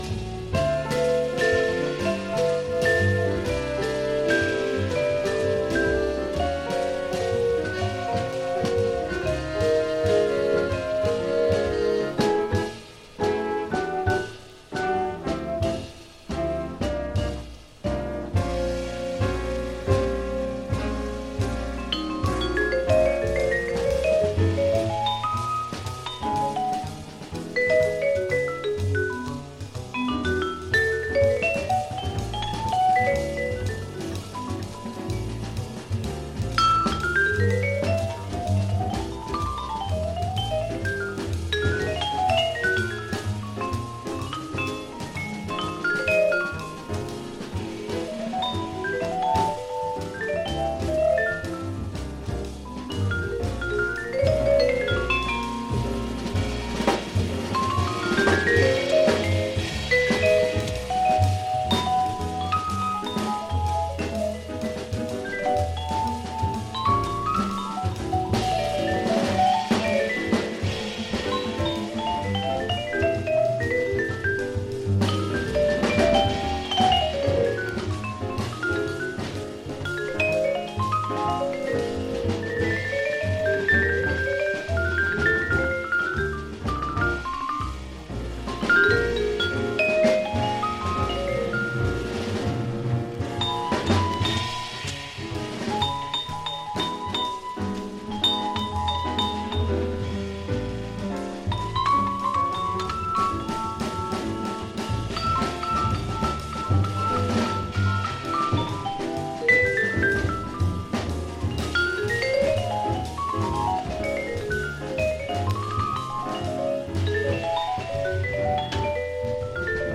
With five cool, funky jazz tracks
pianist
infused with the relaxed, sunny vibe
Funk Jazz